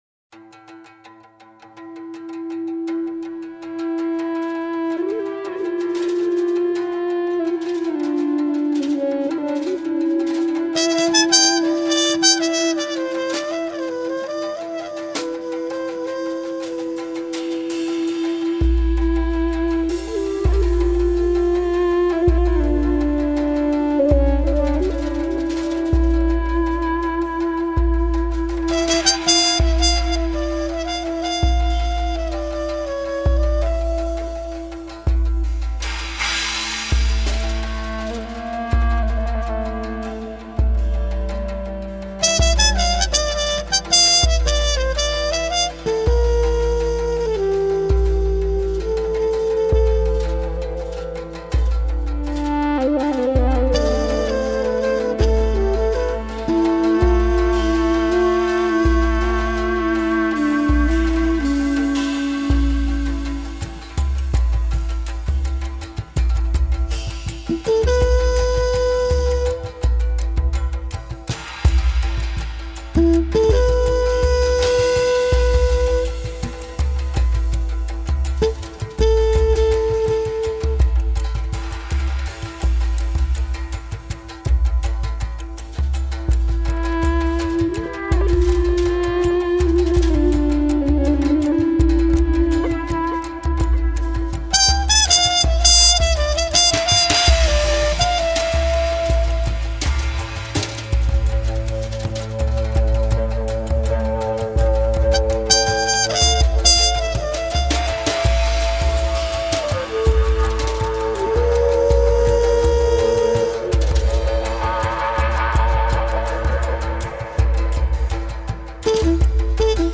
流派：爵士/民谣